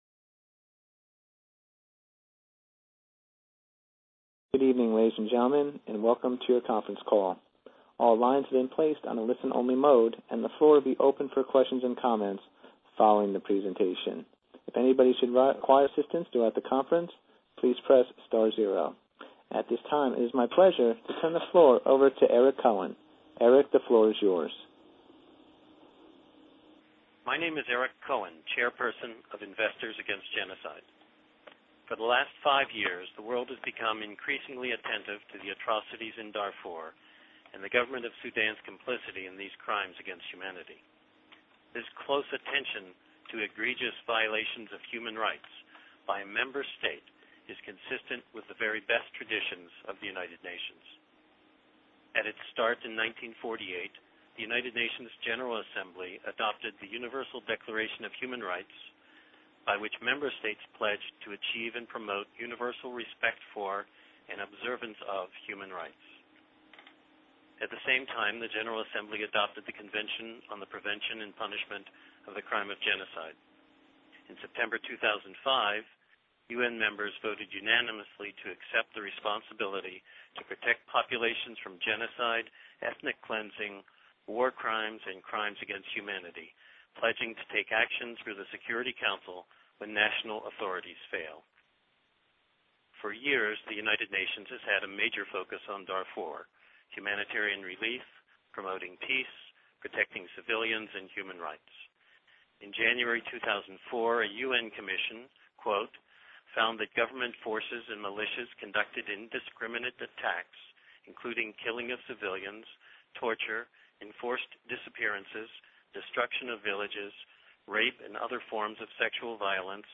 Investors Against Genocide hosted a press conference on the release of the letter on May 12, 2008. The press conference featured short statements, followed by Q&A with the press.